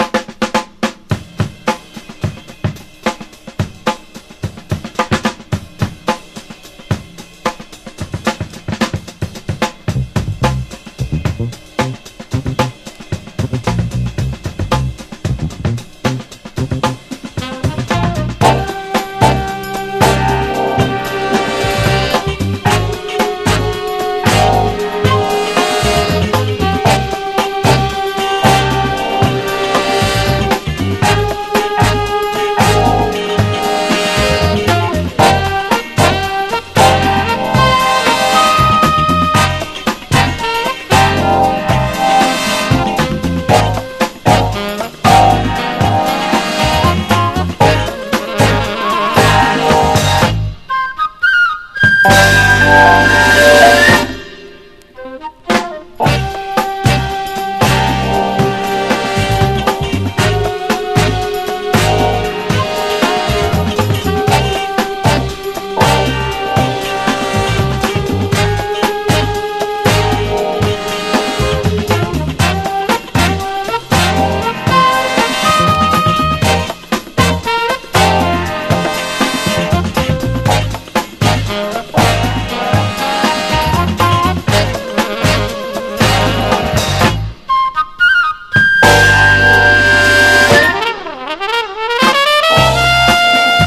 ROCK / 60'S / MERSEY BEAT / BRITISH BEAT